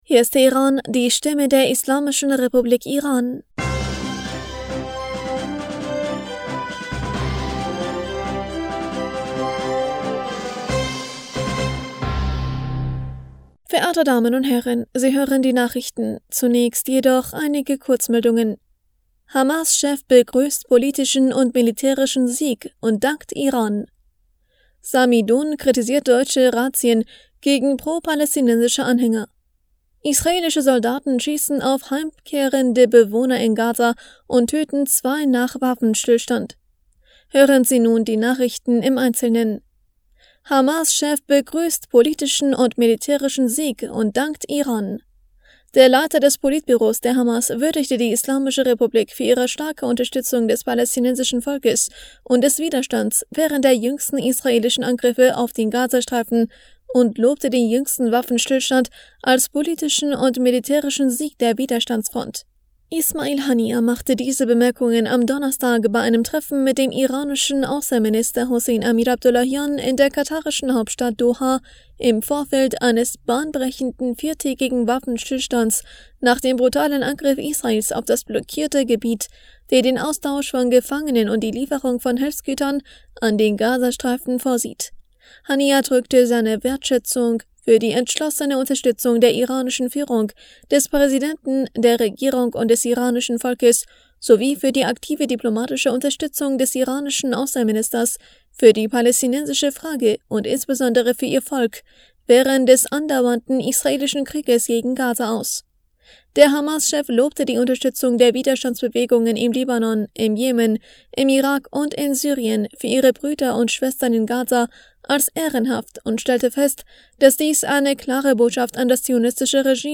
Nachrichten vom 24. November 2023